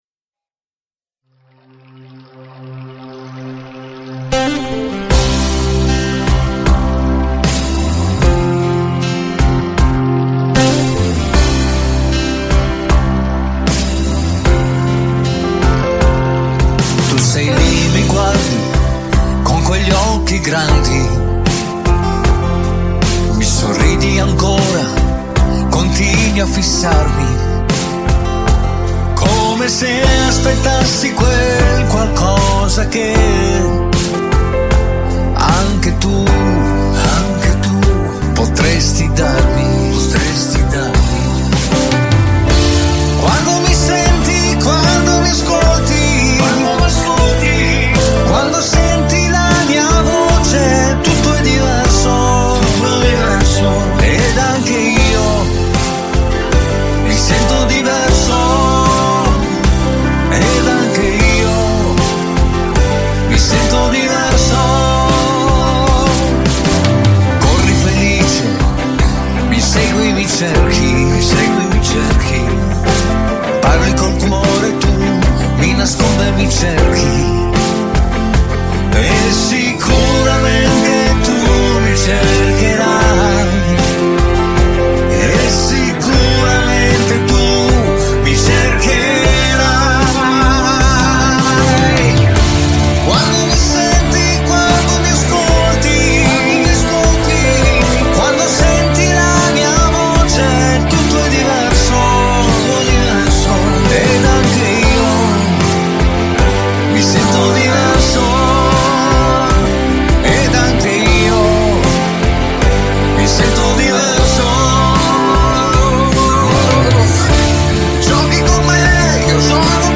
cantautore e pianista